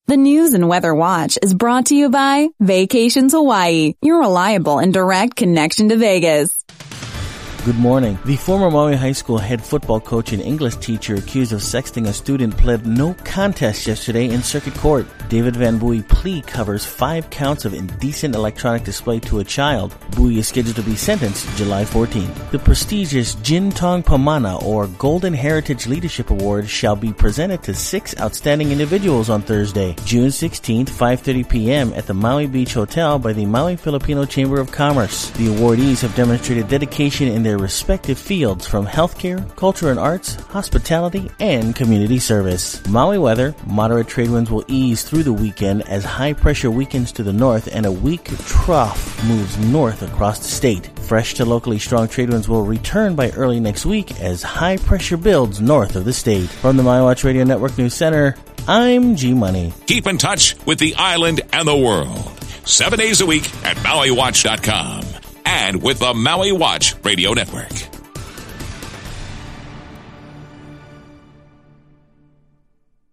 Your daily news & weather brief for May 13, 2016